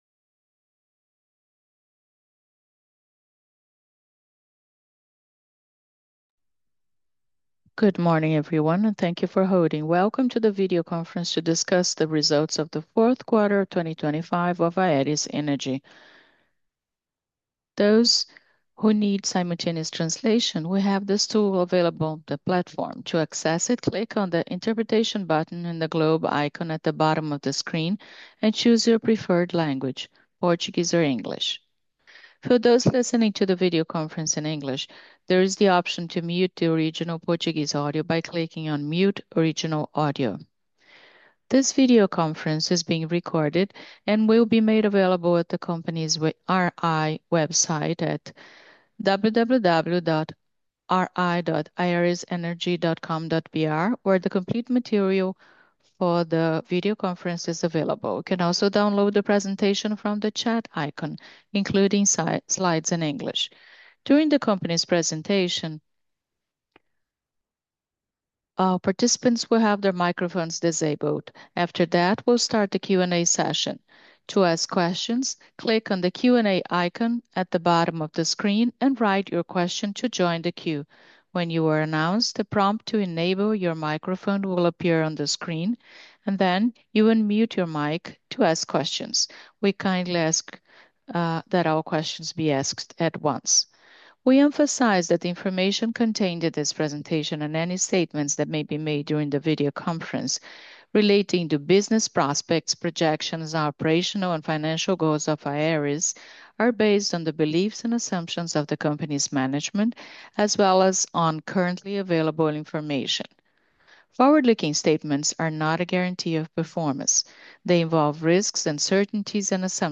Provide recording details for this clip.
teleconferencia_4q25.mp3